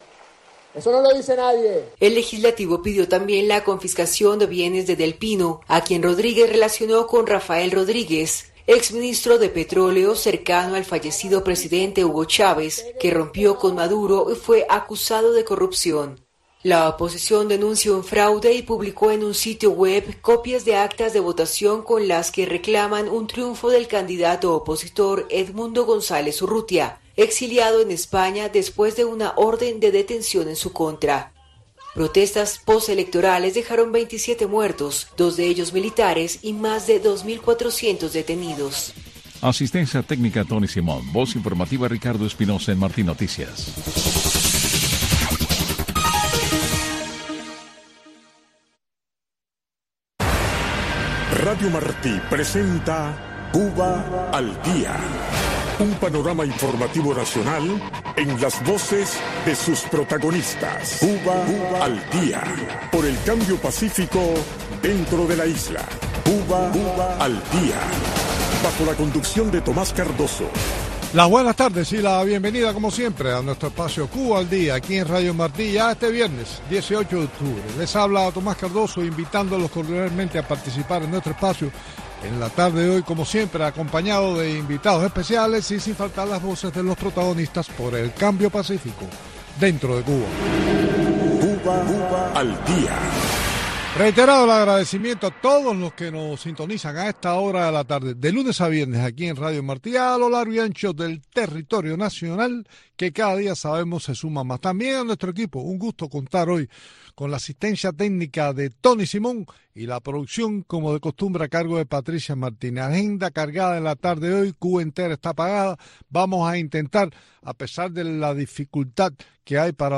en este espacio informativo en vivo